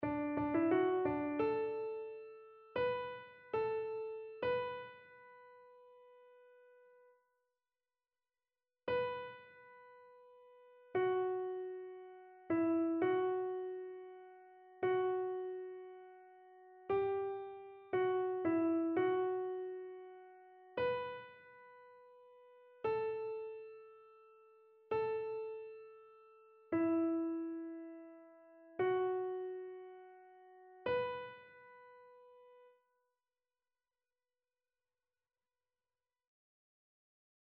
Chœur
annee-b-temps-ordinaire-9e-dimanche-psaume-80-soprano.mp3